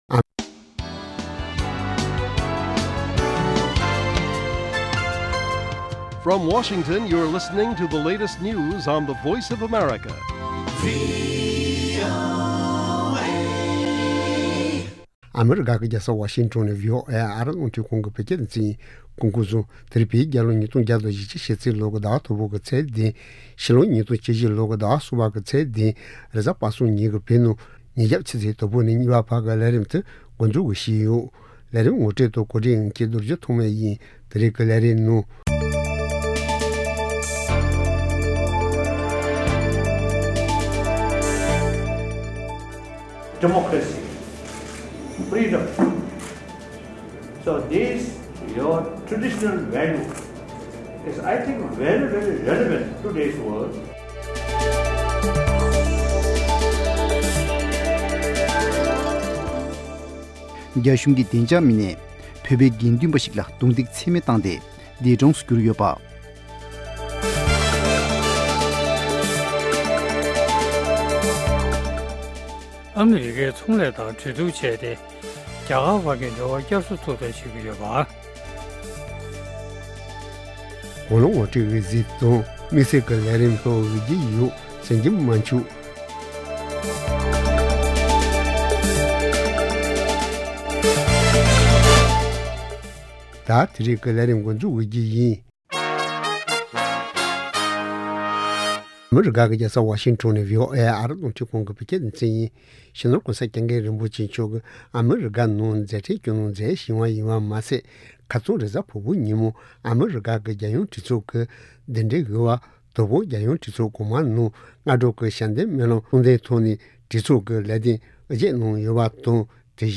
ཉིན་ལྟར་ཐོན་བཞིན་པའི་བོད་དང་ཨ་རིའི་གསར་འགྱུར་ཁག་དང་། འཛམ་གླིང་གསར་འགྱུར་ཁག་རྒྱང་སྲིང་ཞུས་པ་ཕུད།